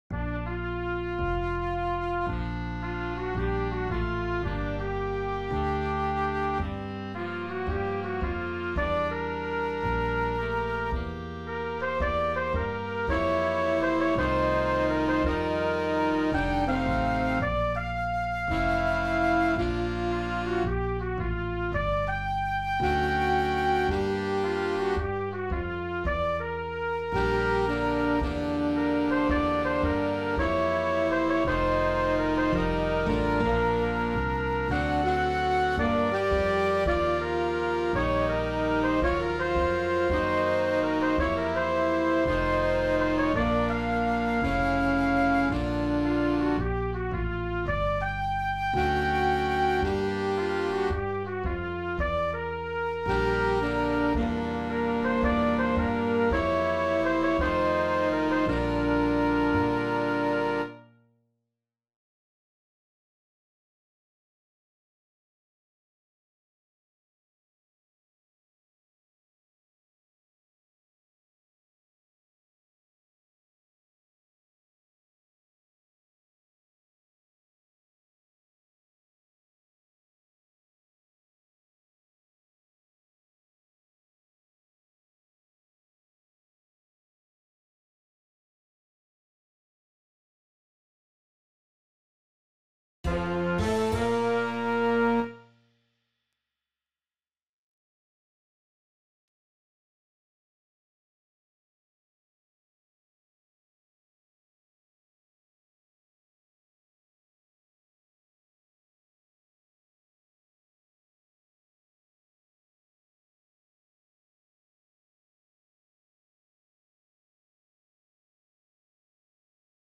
Jazz - Ballad swing - 81